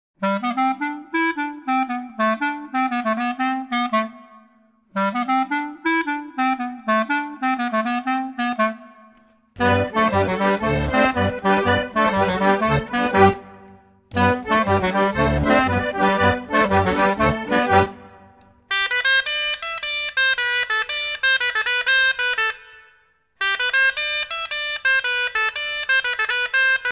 danse-jeu : quadrille : trompeuse
Pièce musicale éditée